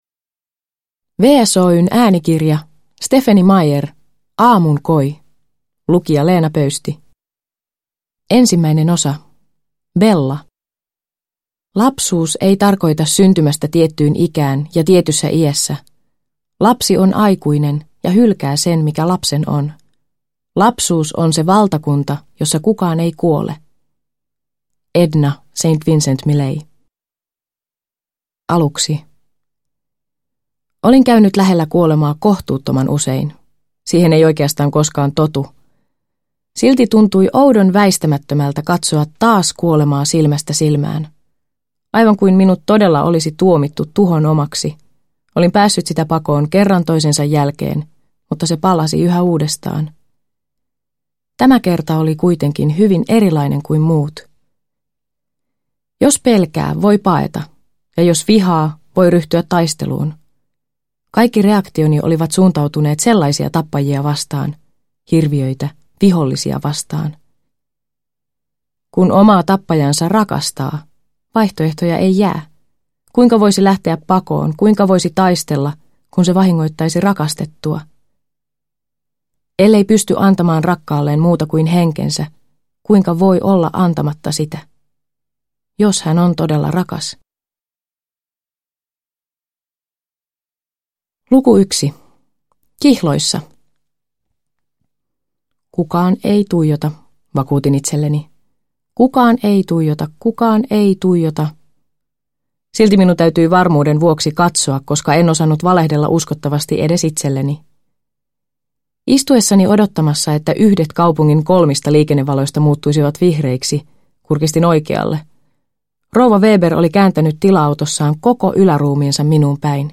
Aamunkoi – Ljudbok – Laddas ner